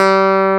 CLAV C3.wav